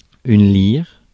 Ääntäminen
Ääntäminen France: IPA: /liʁ/ Haettu sana löytyi näillä lähdekielillä: ranska Käännös Substantiivit 1. lüüra Suku: f .